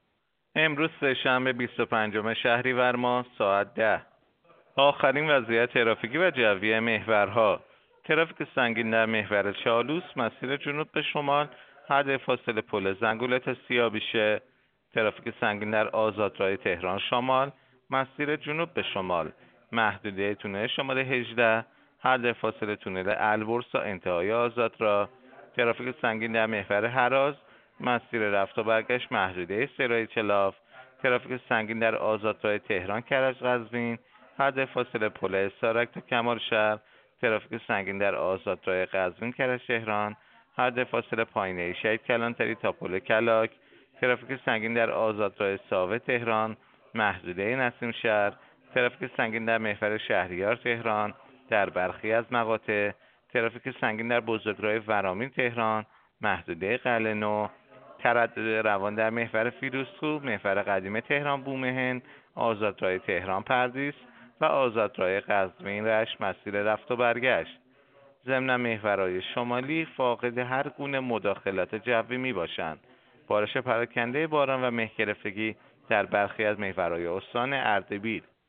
گزارش رادیو اینترنتی از آخرین وضعیت ترافیکی جاده‌ها ساعت ۱۰ بیست و پنجم شهریور؛